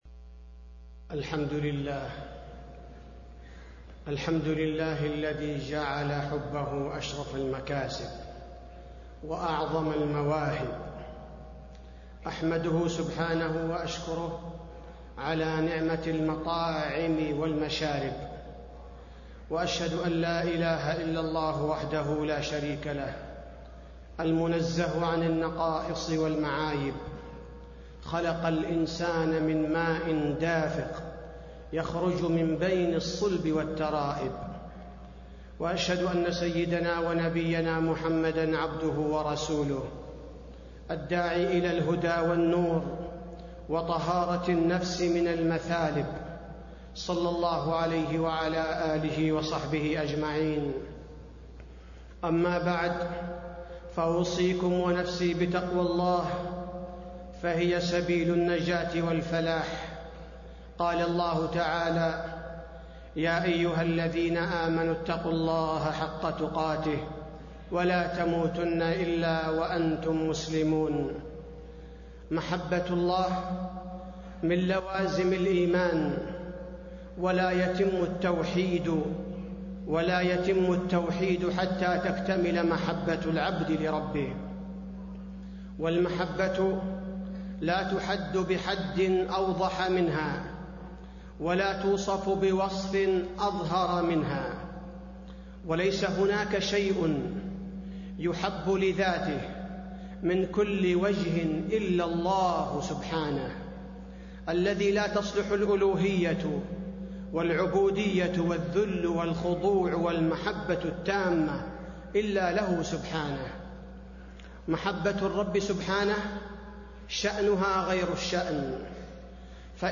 تاريخ النشر ٢٤ جمادى الأولى ١٤٣٤ هـ المكان: المسجد النبوي الشيخ: فضيلة الشيخ عبدالباري الثبيتي فضيلة الشيخ عبدالباري الثبيتي محبة الله والأسباب الجالبة لها